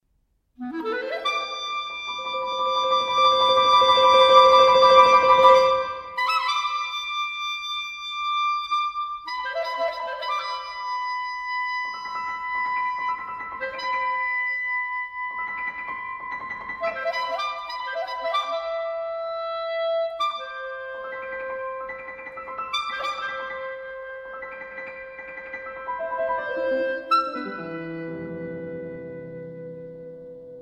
Flute
Clarinet
Piano.